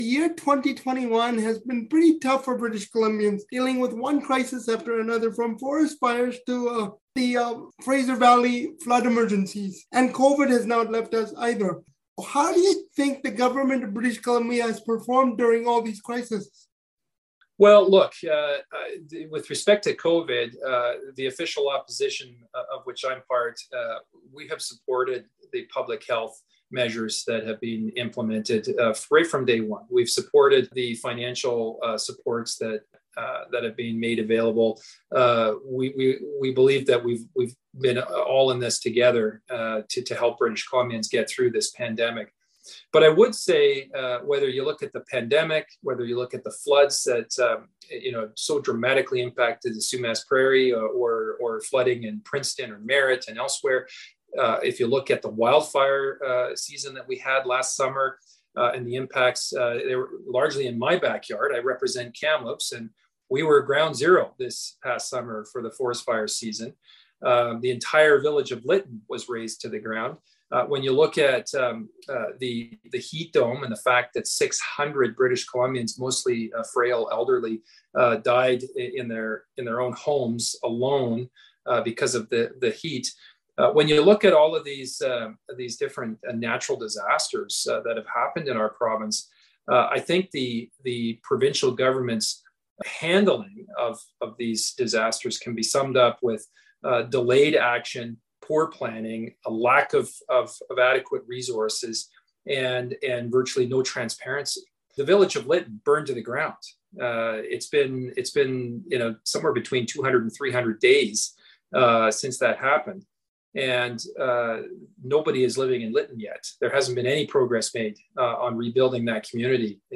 The MLA believes there's been delayed reaction and no transparency, on the BC government's part, and a general lack of adequate resources. Listen to CIVL's interview with Stone below: Download Audio Prev Previous Post COVID-19 vaccines available again this week at area pharmacies Next Post Sackville to ask CN not to use glyphosate inside town boundaries Next